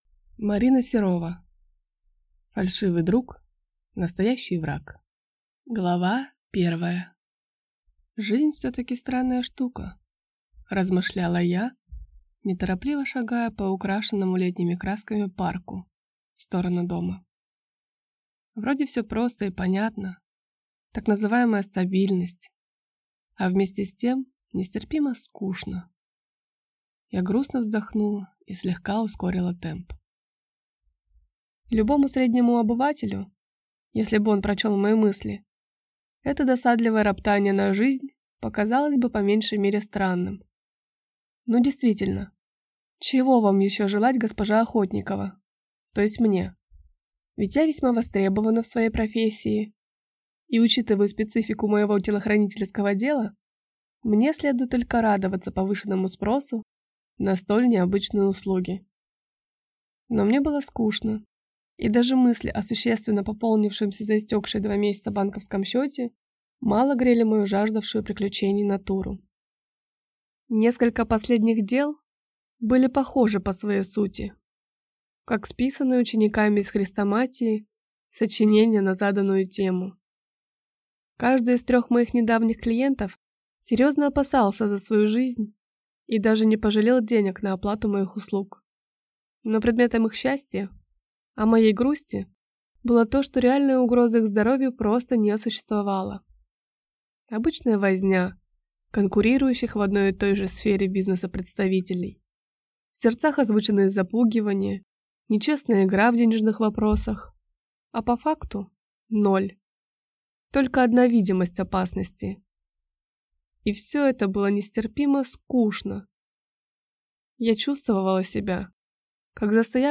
Аудиокнига Фальшивый друг, настоящий враг | Библиотека аудиокниг